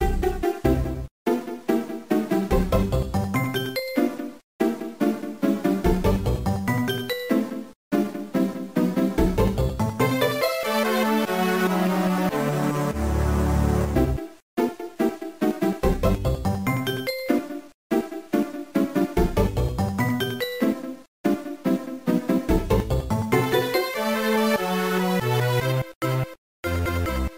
Clipped and applied fade-out with Audacity.